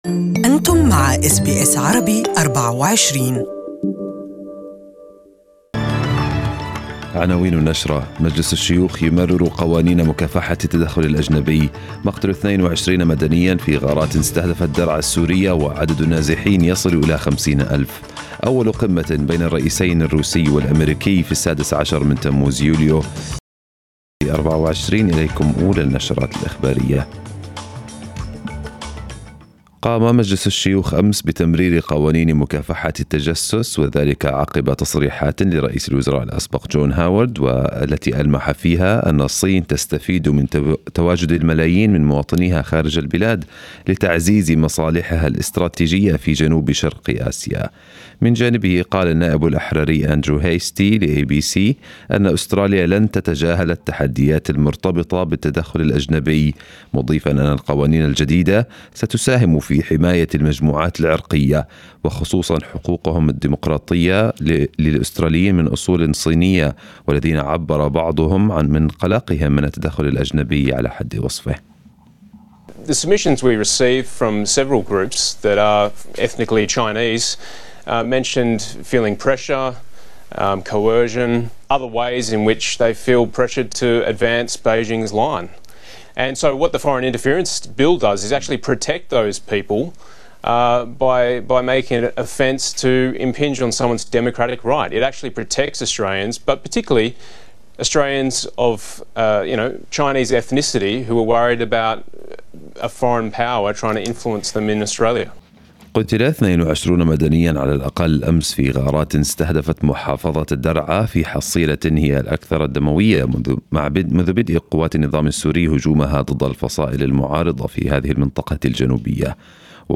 Arabic News Bulletin 29/06/2018